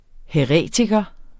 Udtale [ hεˈʁεˀtigʌ ]